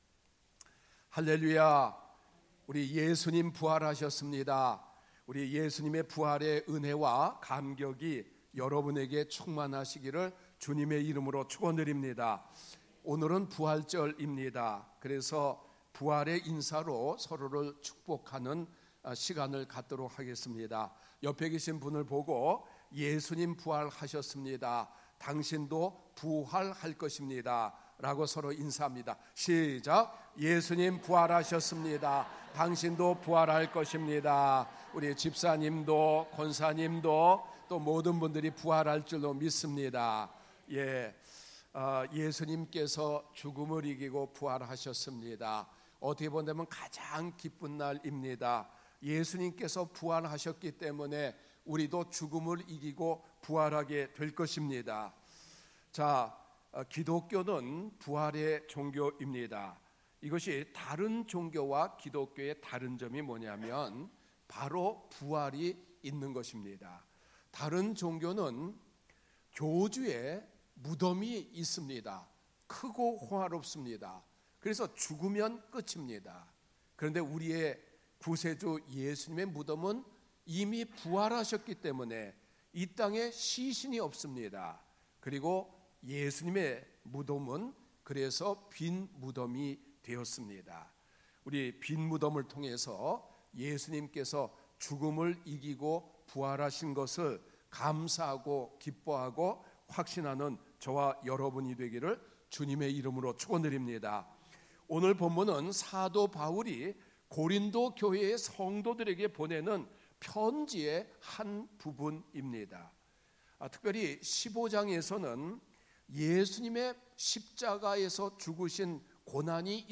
부활주일예배 2부 설교